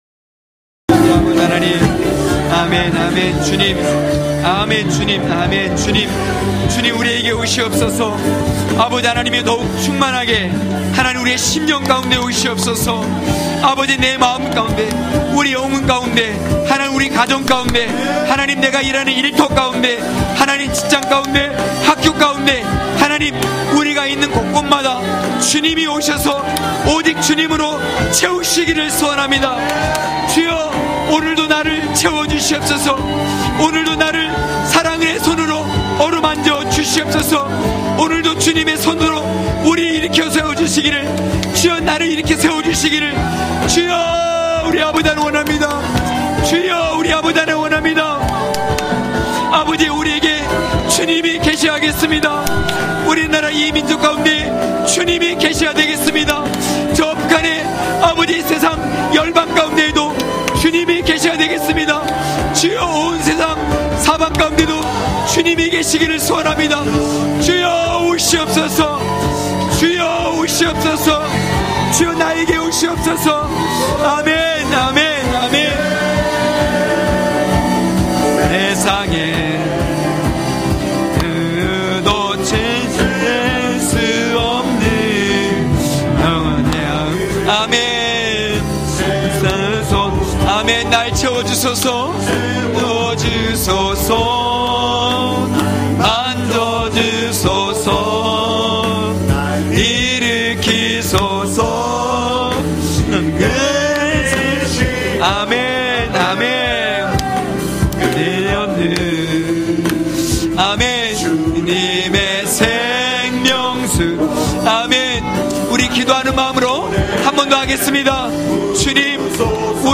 강해설교 - 11.경외함이 중심(느6장1~9절).mp3